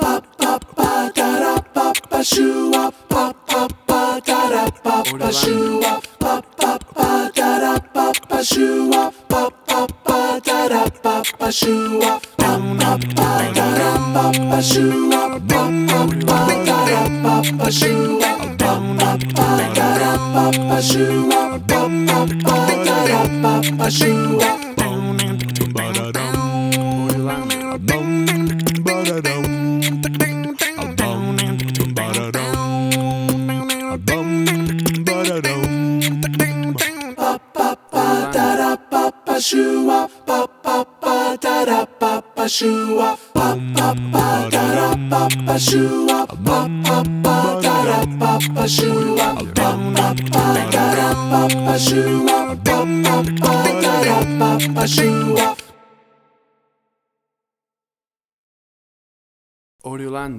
WAV Sample Rate: 16-Bit stereo, 44.1 kHz
Tempo (BPM): 156